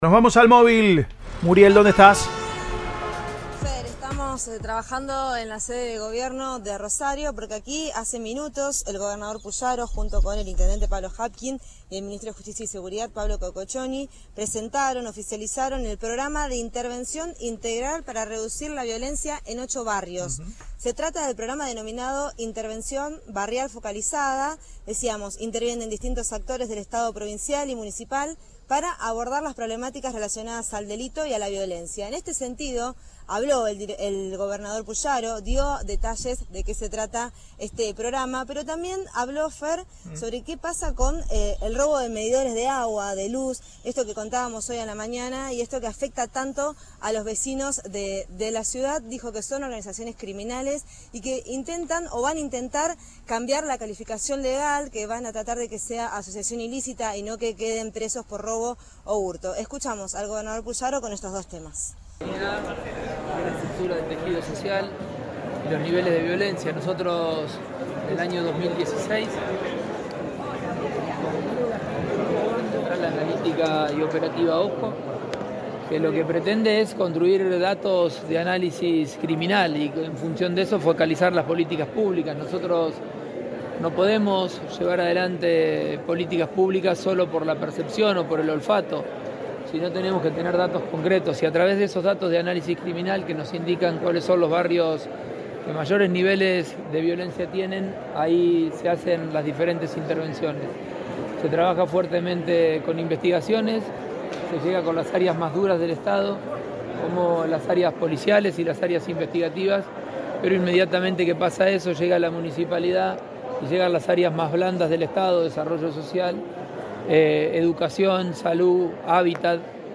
Desde la sede de Gobierno, Pullaro indicó al móvil de Cadena 3 Rosario que la implementación del OJO fue clave ya que “no se puede llevar adelante políticas públicas por el olfato, necesitamos datos concretos en los que conocemos los barrios de mayores niveles de violencia”.